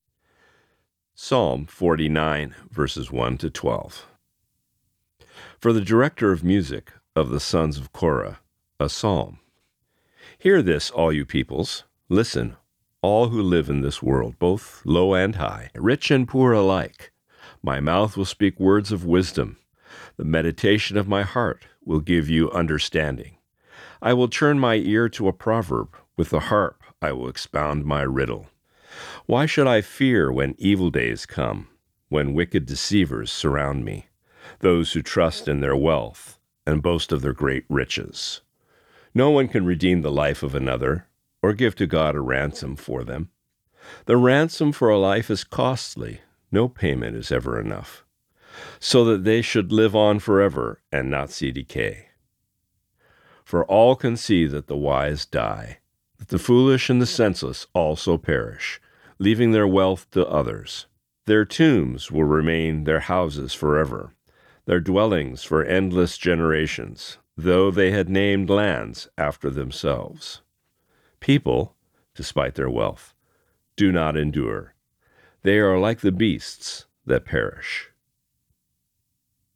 Today’s Reading: Psalm 49:1-12